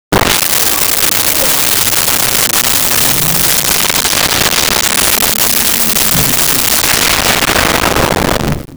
Whale Moan 02
Whale Moan 02.wav